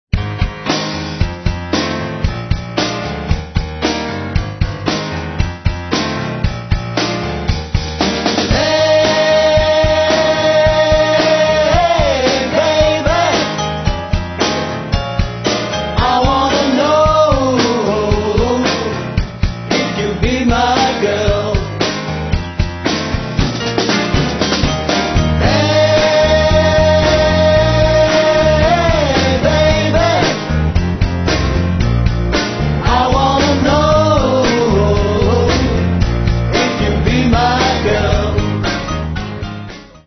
17 Titres country et Rock n’ roll